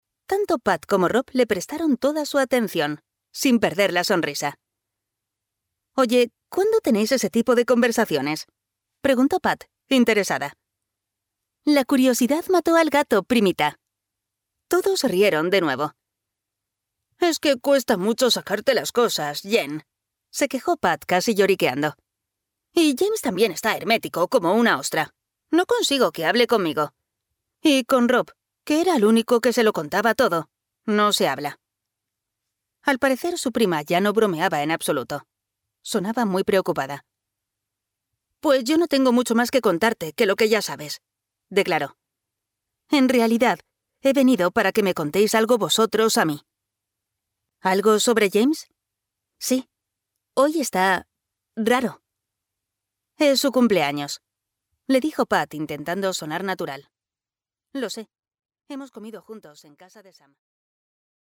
Audiolibro Tentados por el Destino (Tempted by Destiny)